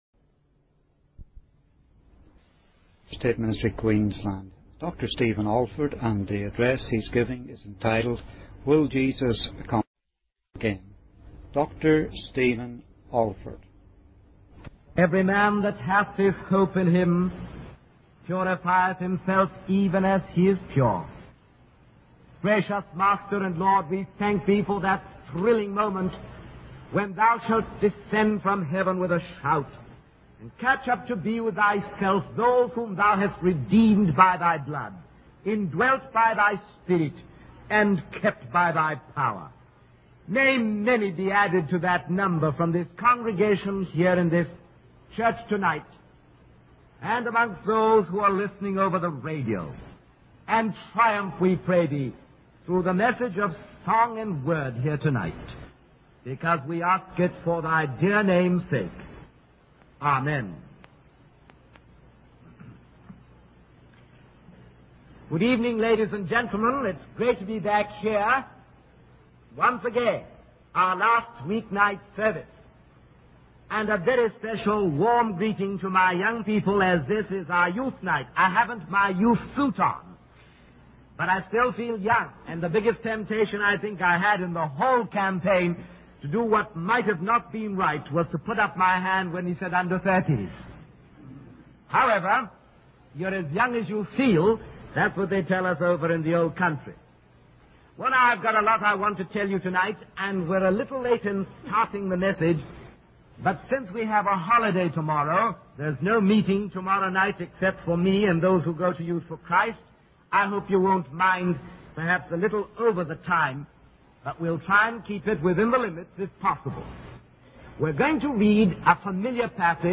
In this sermon, the preacher discusses the idea that instead of evolving, humanity is actually devolving and deteriorating.